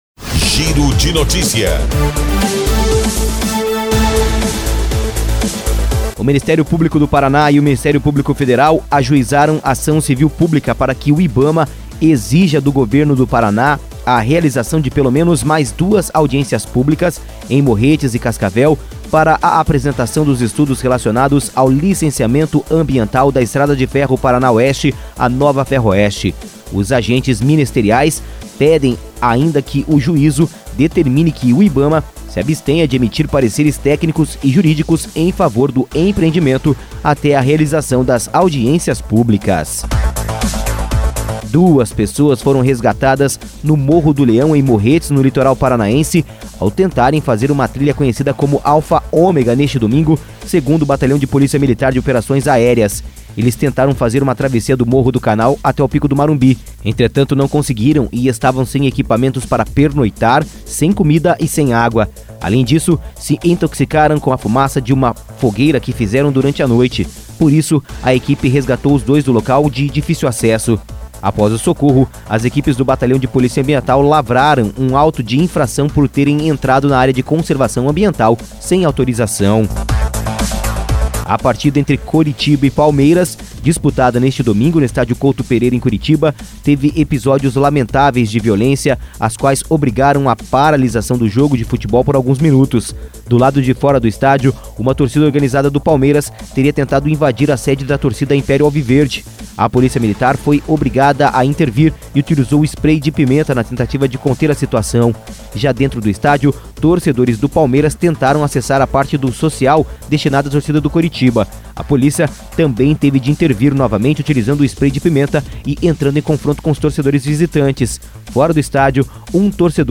Giro de Notícias – Edição da Manhã